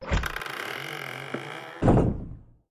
SFX_Toilet_Close.wav